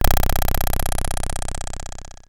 ihob/Assets/Extensions/RetroGamesSoundFX/Hum/Hum37.wav at master
Hum37.wav